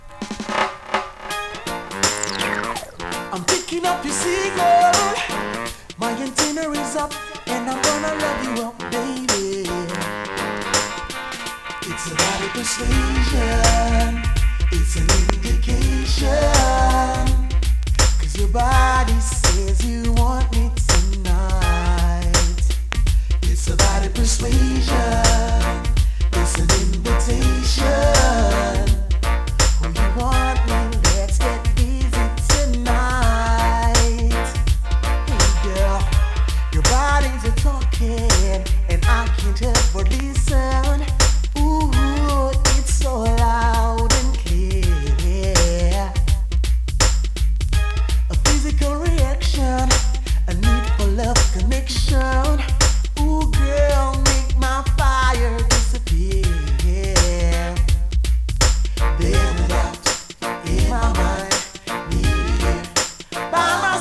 DANCEHALL!!
スリキズ、ノイズ比較的少なめで